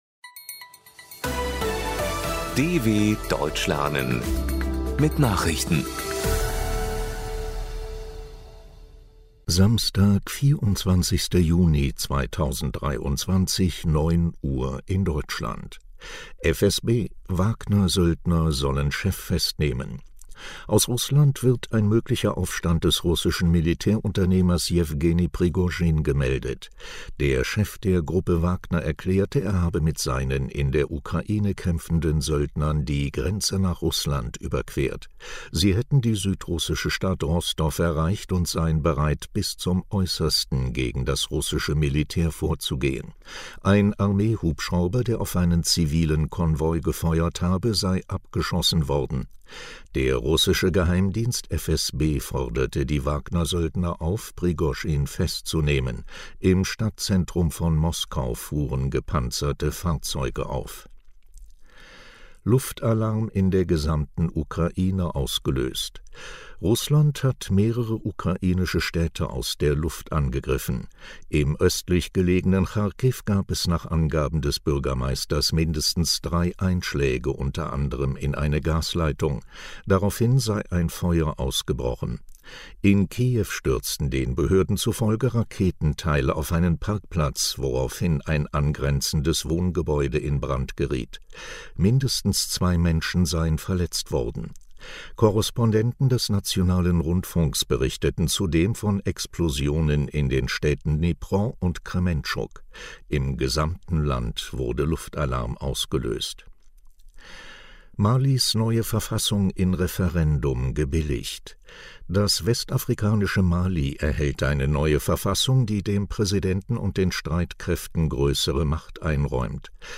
24.06.2023 – Langsam Gesprochene Nachrichten
Trainiere dein Hörverstehen mit den Nachrichten der Deutschen Welle von Samstag – als Text und als verständlich gesprochene Audio-Datei.